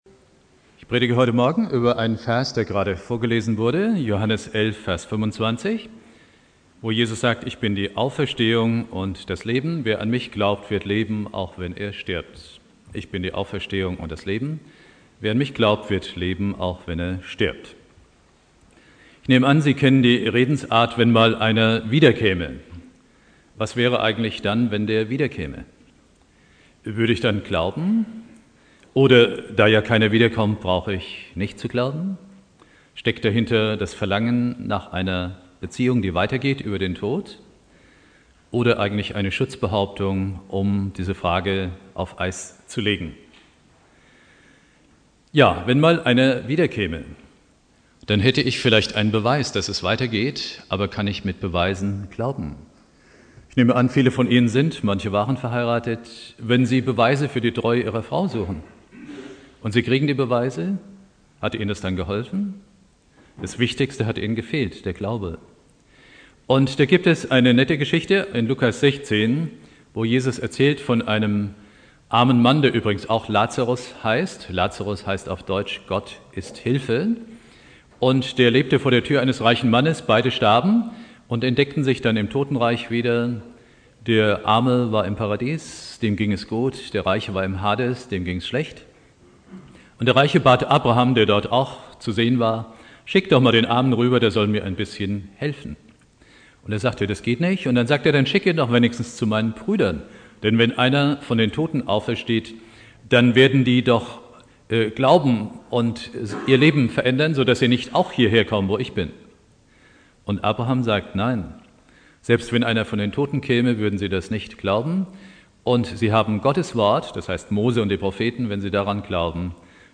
Predigt
Ewigkeitssonntag